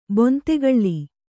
♪ bontegaḷḷi